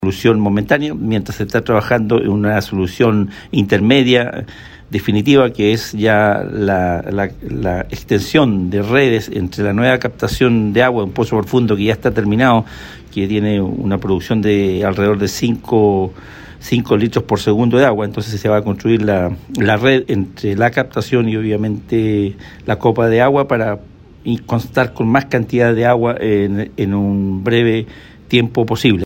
El personero provincial subrayó que esta situación, de abastecer por medio de los camiones aljibes, es solo una solución momentánea puesto que lo definitivo irá más adelante, con la conexión a un nuevo punto de aprovisionamiento de agua para la comunidad.